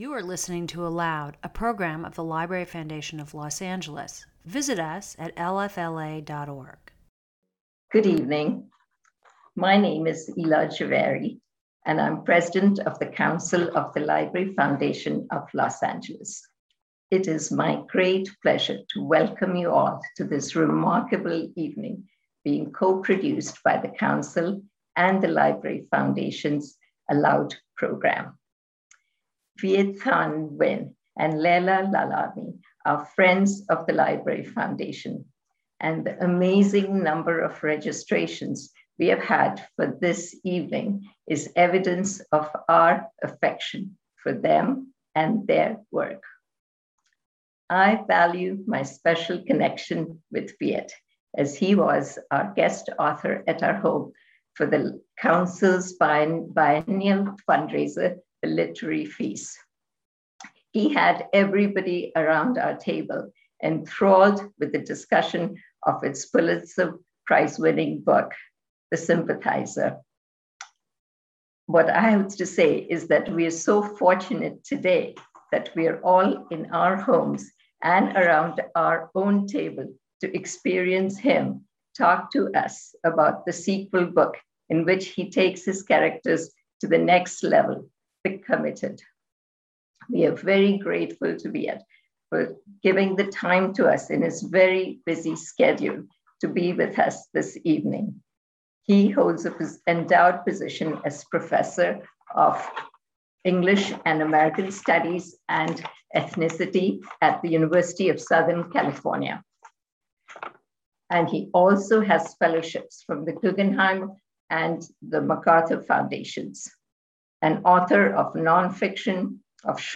Viet Thanh Nguyen In Conversation With Laila Lalami